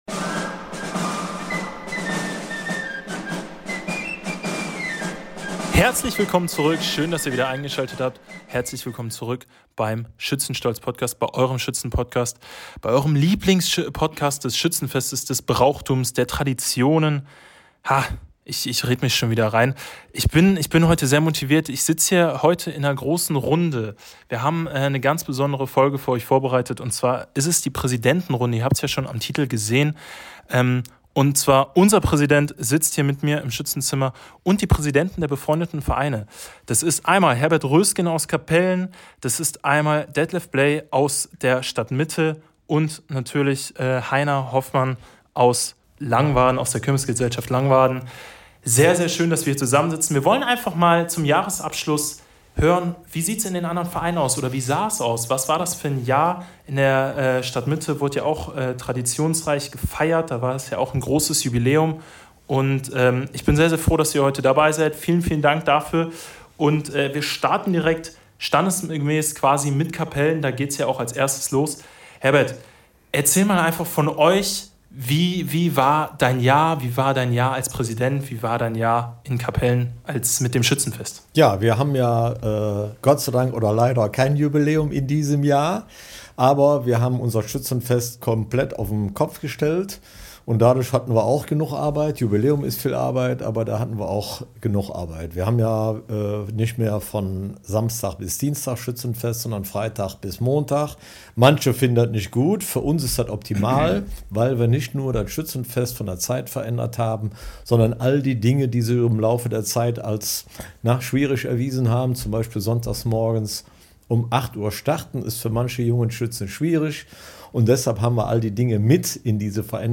In dieser Episode sprechen die Präsidenten der Schützenvereine aus dem Grevenbroicher Stadtgebiet und Umgebung über die Höhepunkte des Schützenjahres, die Herausforderungen der Vereinsarbeit und ihre Visionen für die Zukunft des Schützenwesens.